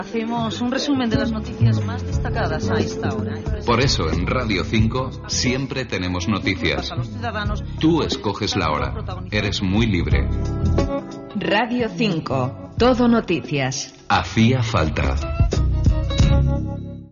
Promoció de l'emissora